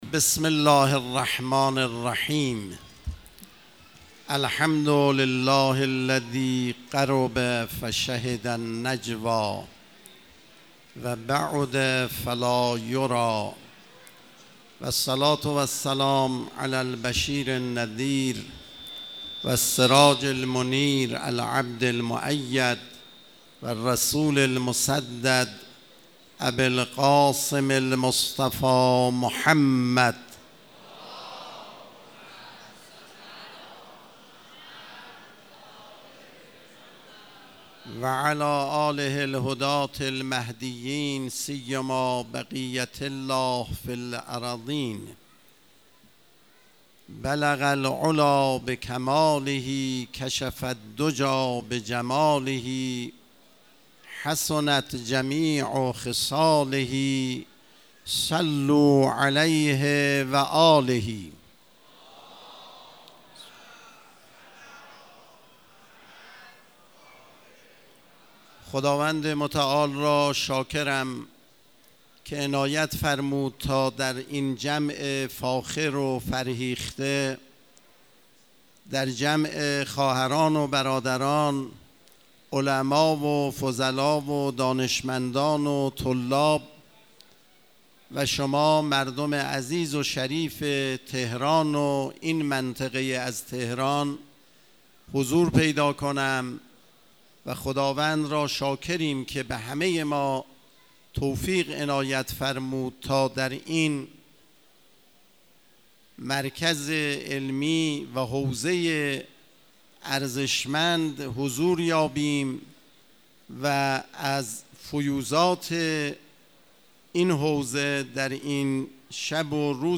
سخنرانی آیت الله اعرافی
گزارش تصویری عمامه‌گذاری و تکریم حفاظ قرآن مدرسه علمیه دارالسلام تهران